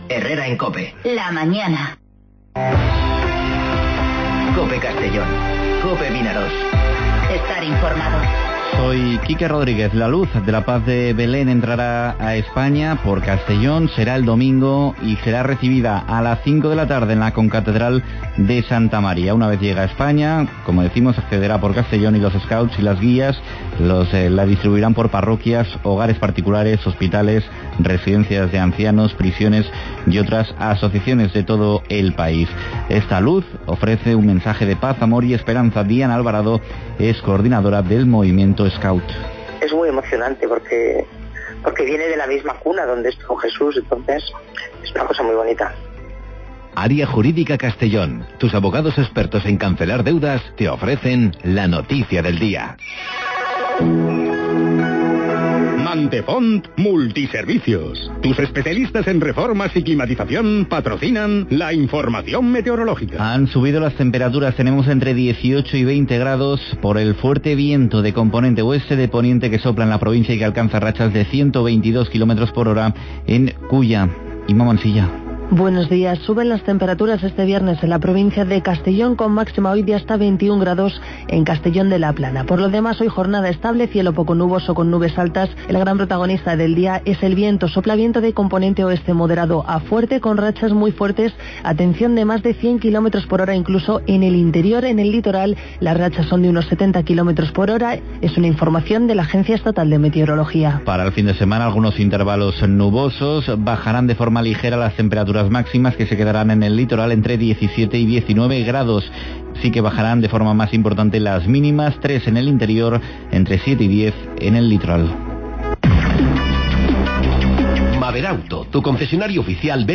Informativo Herrera en COPE Castellón (13/12/2019)